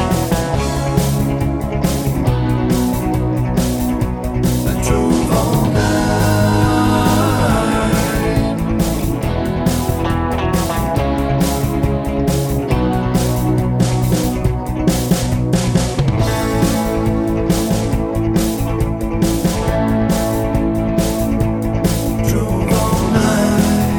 One Semitone Up Pop (1960s) 3:52 Buy £1.50